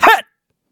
Kibera-Vox_Attack4_kr.wav